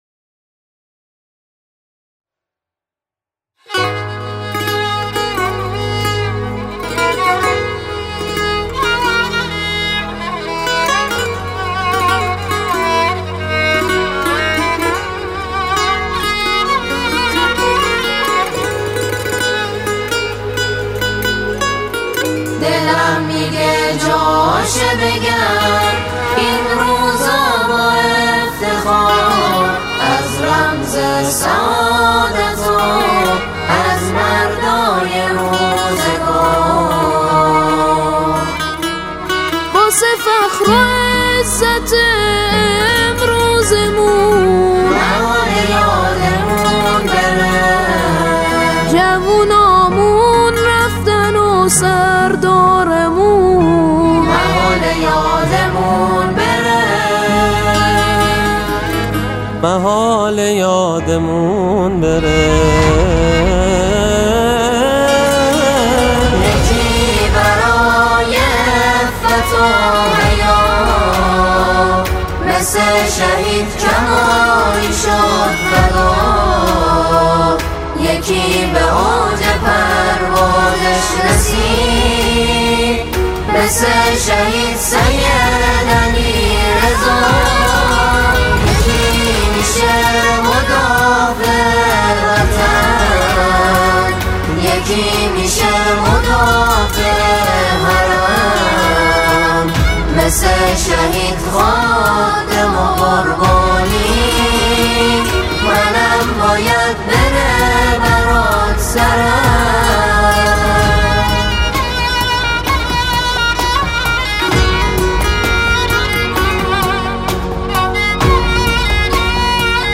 سرودهای شهدا